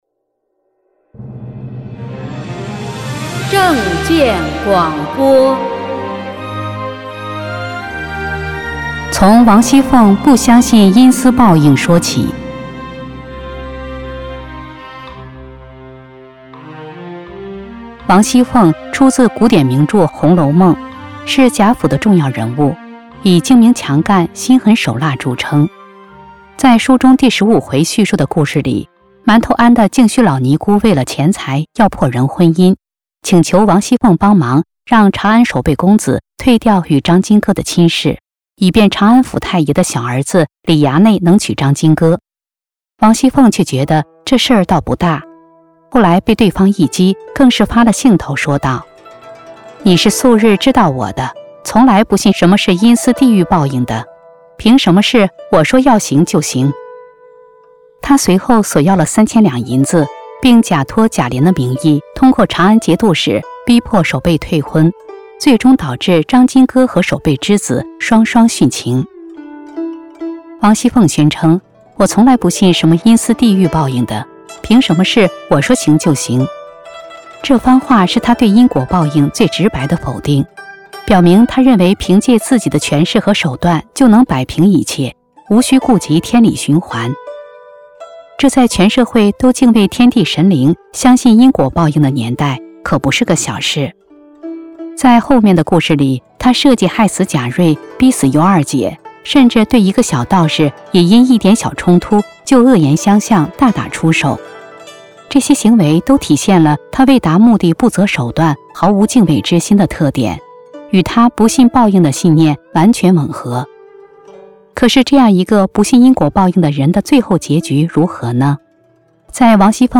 播音：